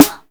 SNARE.12.NEPT.wav